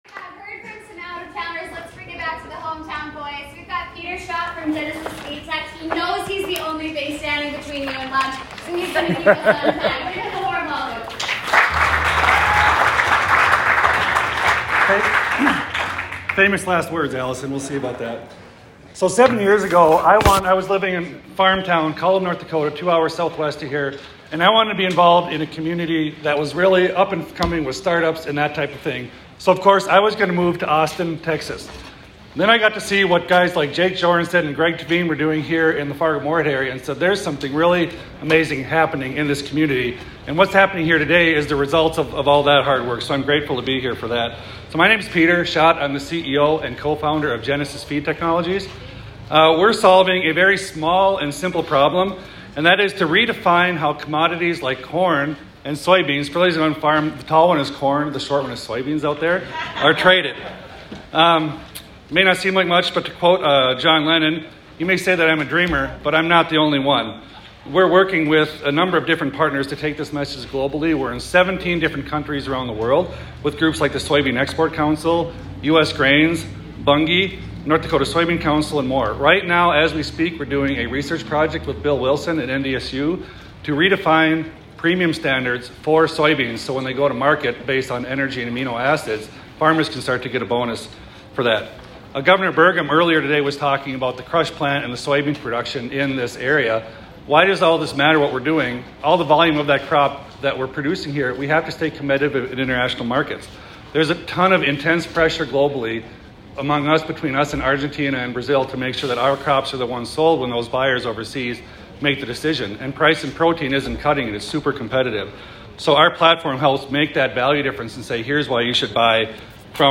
at the 2021 Cultivate Conference in Fargo, ND